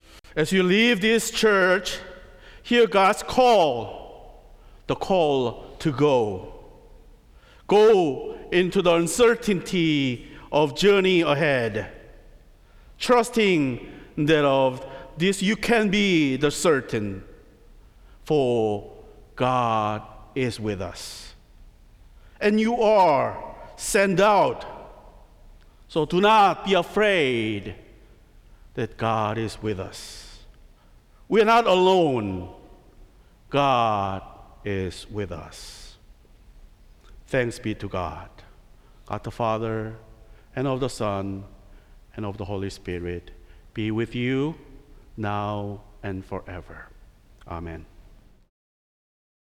Service of Worship
Benediction